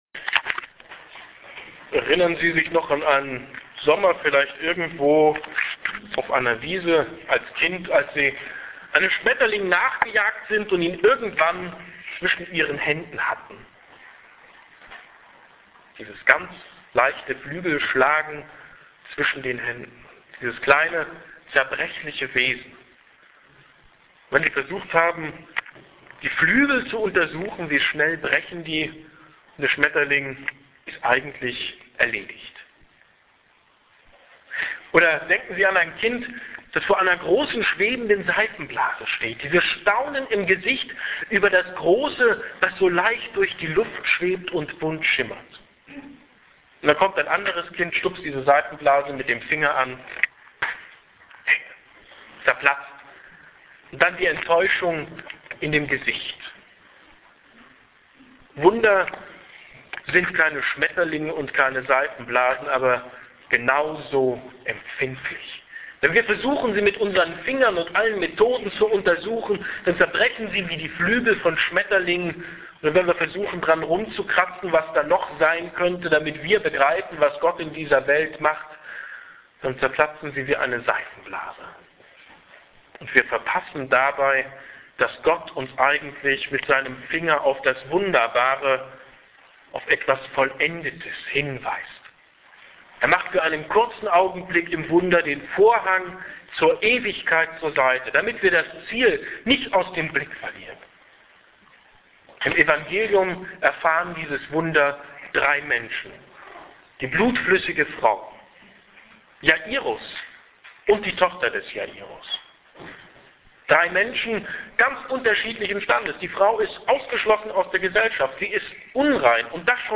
hier-klickt-die-predigt1.mp3